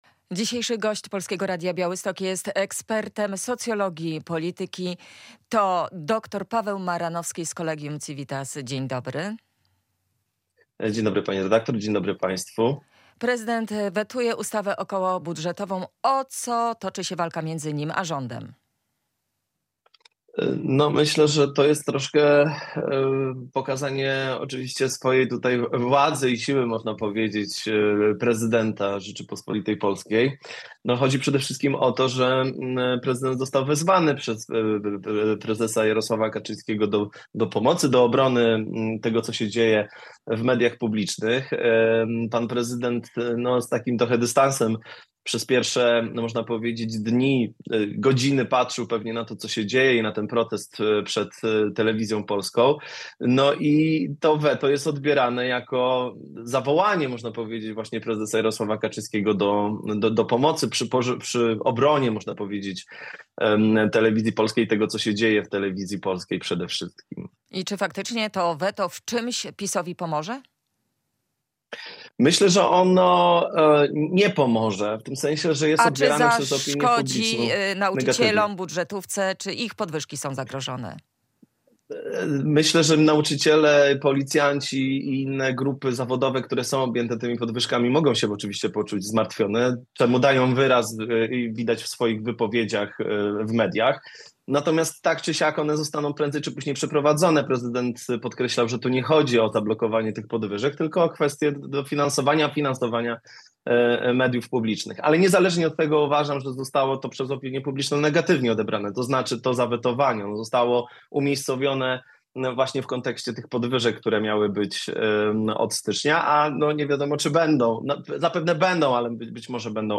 O tym jak w Polsce następuje przejmowanie władzy z ekspertem socjologii polityki